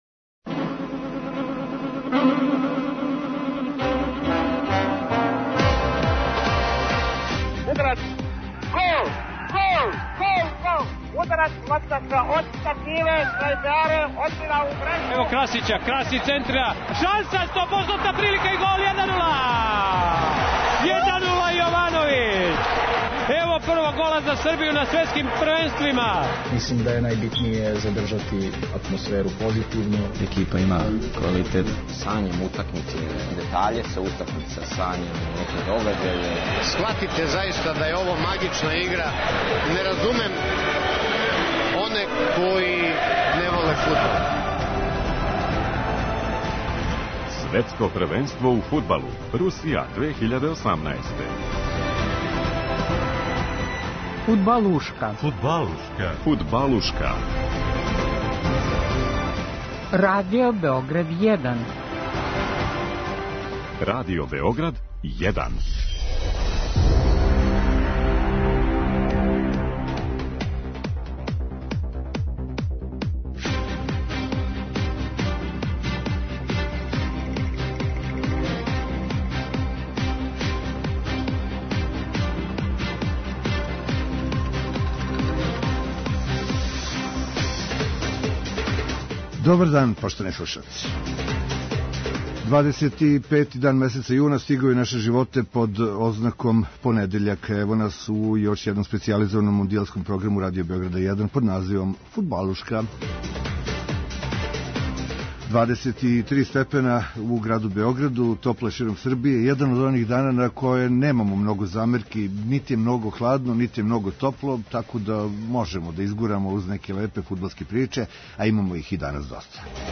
Наших навијача свуда има, ведри су, уносе позитиван дух и исте такве вибрације - овога пута чућемо српског фана из Берлина, који је превалио велики пут да гледа своје и наше љубимце.